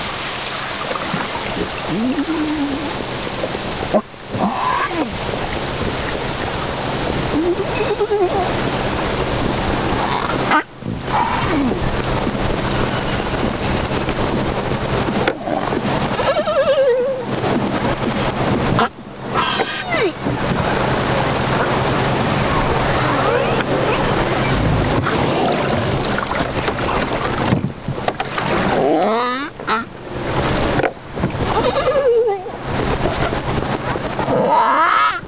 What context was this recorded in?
The ship departed Ushuaia, Argentina at the end of last year and has been doing research in Antarctic waters for the last 1 1/2 months, including whale identification and recording of their underwater sounds, such as this